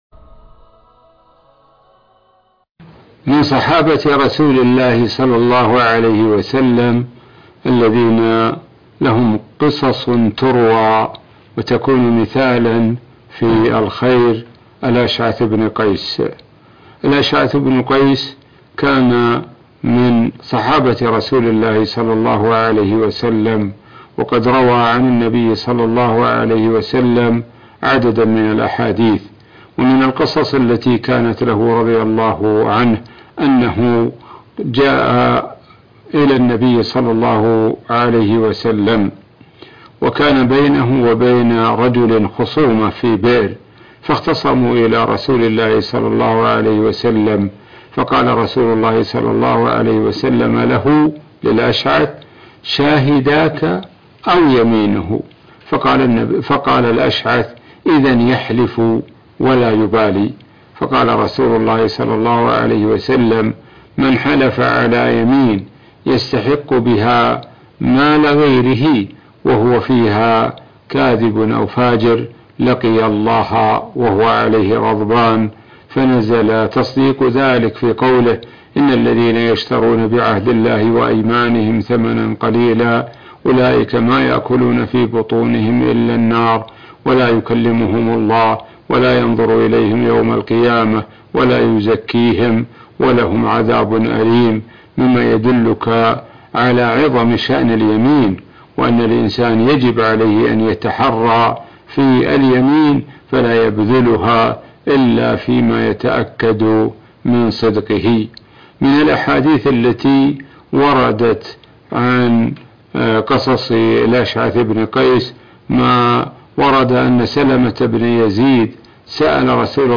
الأيام الخالية - الشيخ د. سعد الشثري يروي قصصًا عن الصحابي الأشعث ابن قيس رضي الله عنه - الشيخ سعد بن ناصر الشثري